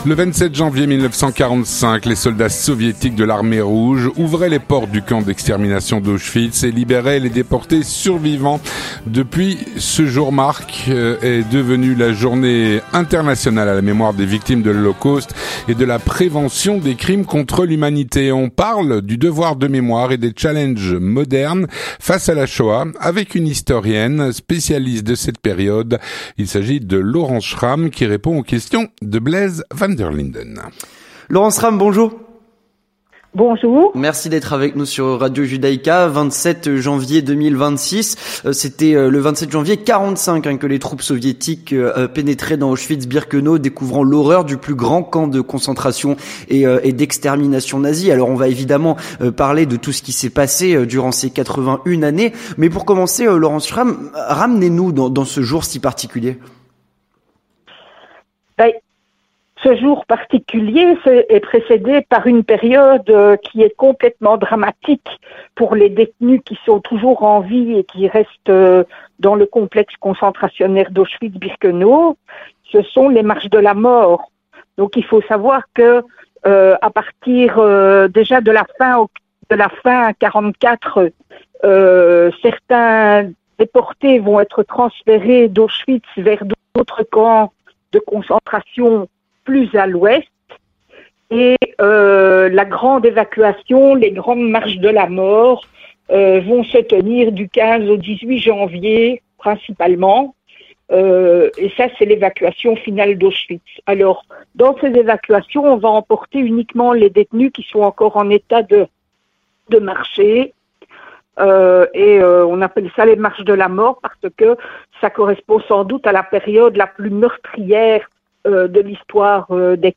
On parle du devoir de mémoire et des challenges modernes face à la shoah avec une historienne, spécialiste de cette période